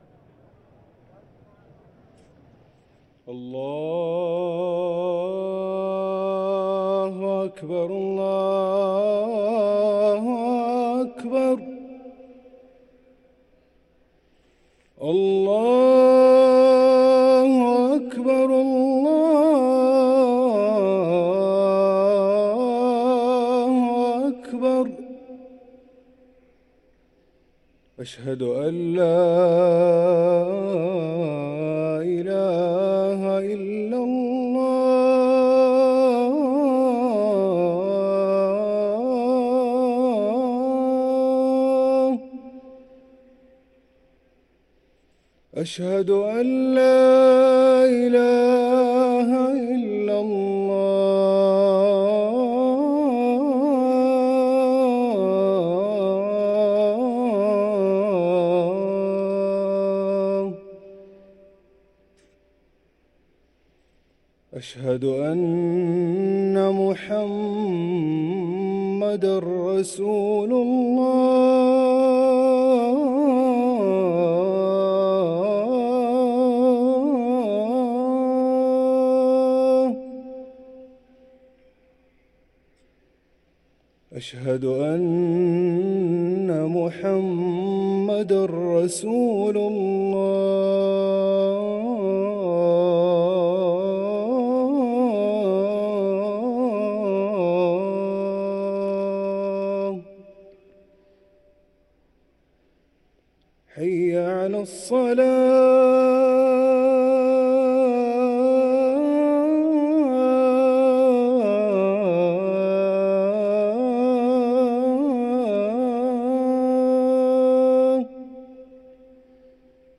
أذان الفجر للمؤذن هاشم السقاف السبت 7 ذو القعدة 1444هـ > ١٤٤٤ 🕋 > ركن الأذان 🕋 > المزيد - تلاوات الحرمين